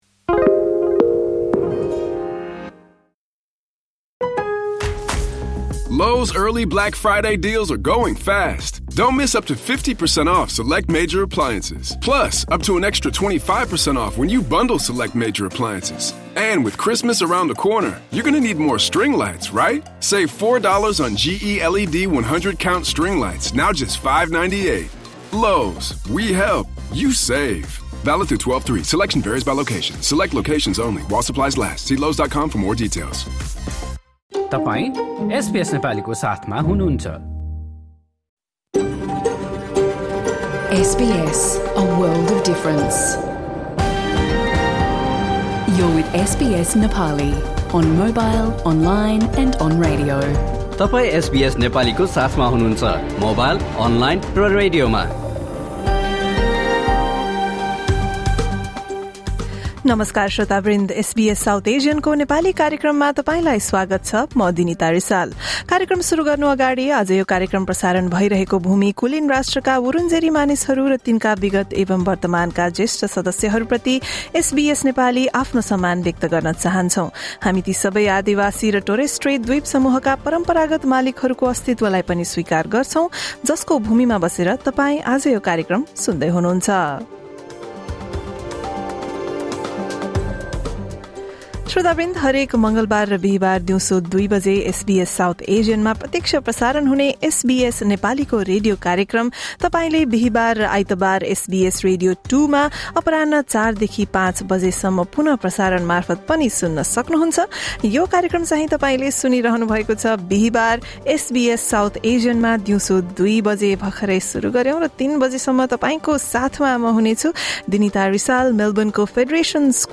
हाम्रो रेडियो कार्यक्रम हरेक मङ्गलवार र बिहीवार दिउँसो दुई बजे SBS South Asian मा प्रत्यक्ष प्रसारण हुन्छ।
Listen to the SBS Nepali program broadcast on Tuesday, 18 November 2025.